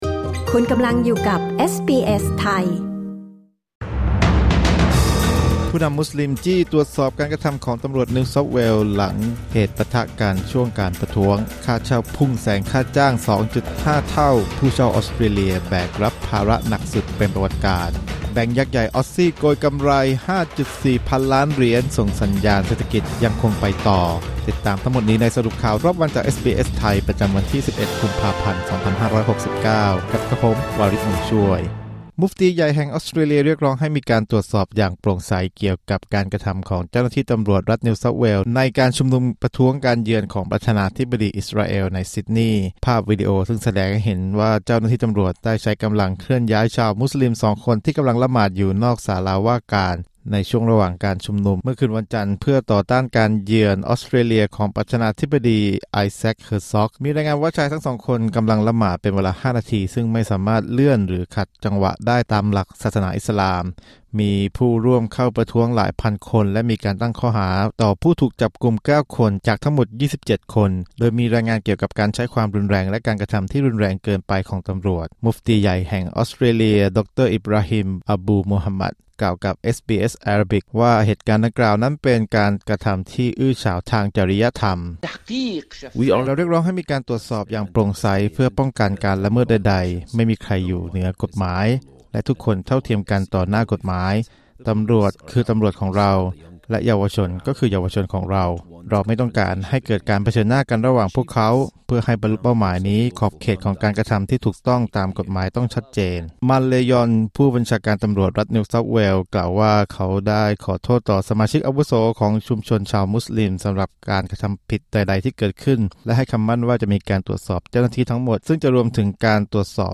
สรุปข่าวรอบวัน 11 กุมภาพันธ์ 2569